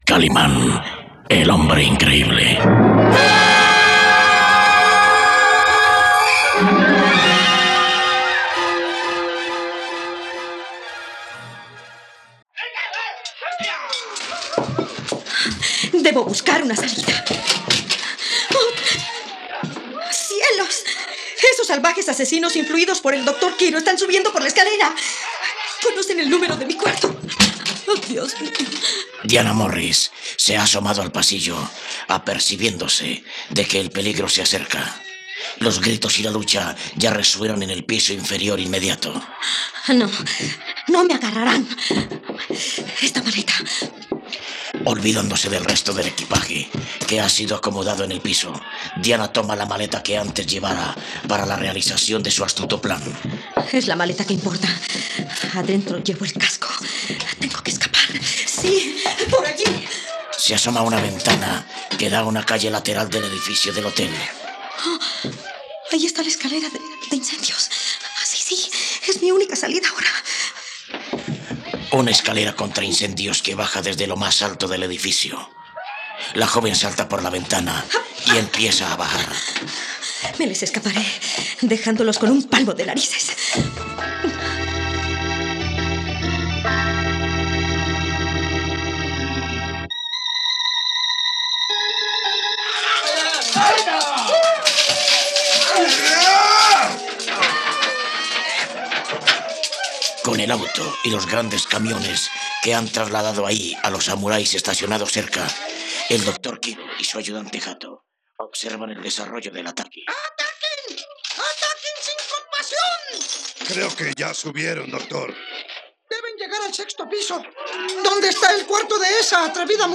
radionovela